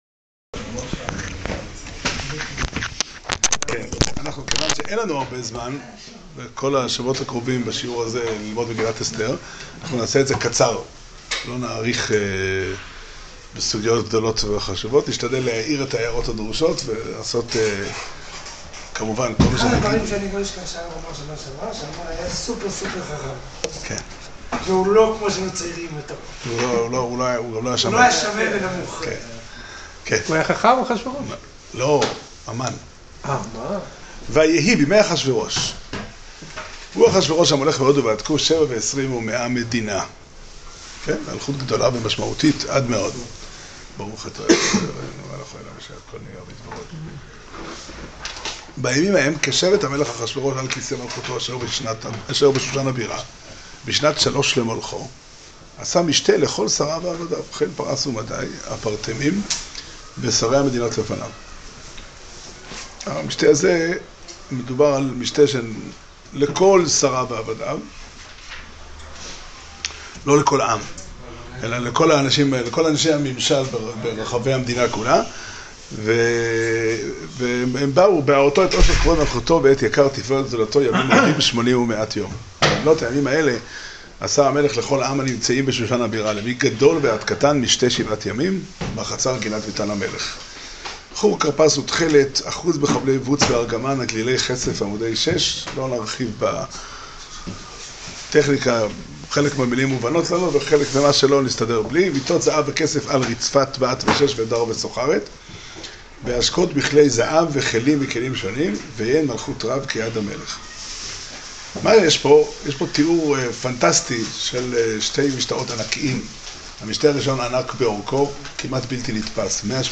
שיעור שנמסר בבית המדרש פתחי עולם בתאריך כ"ז אדר א' תשע"ט